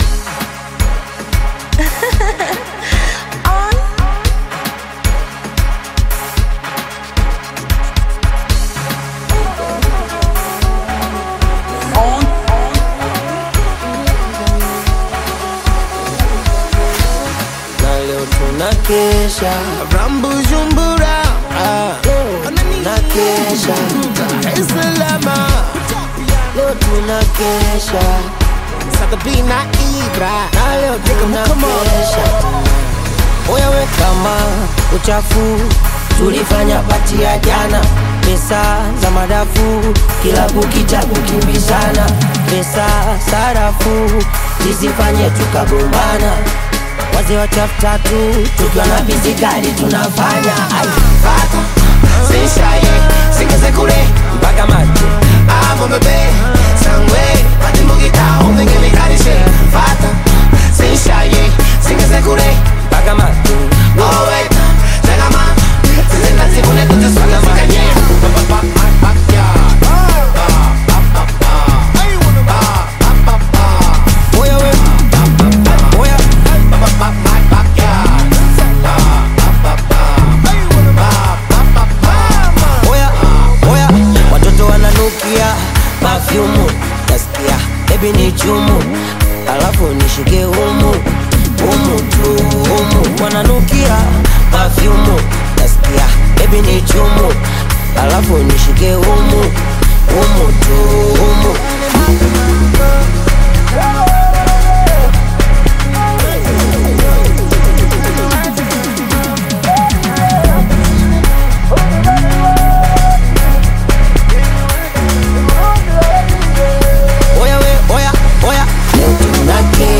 vibrant Afrobeat track